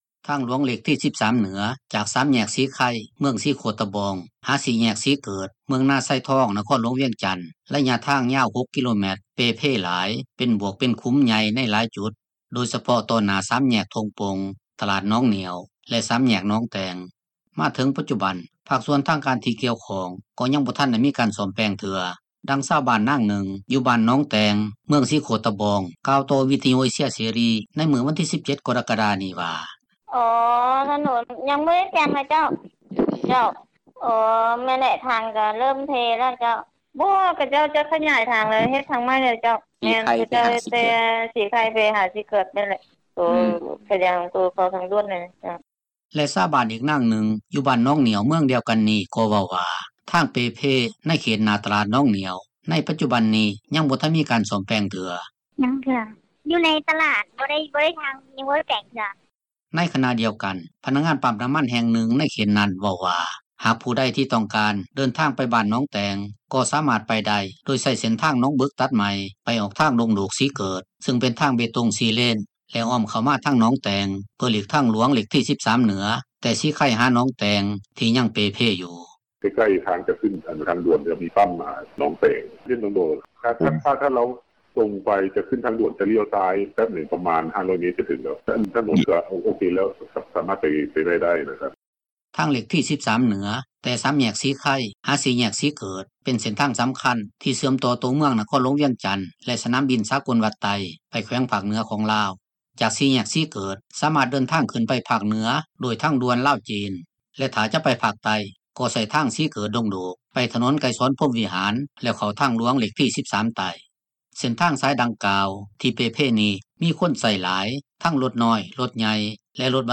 ນັກຂ່າວ ພົລເມືອງ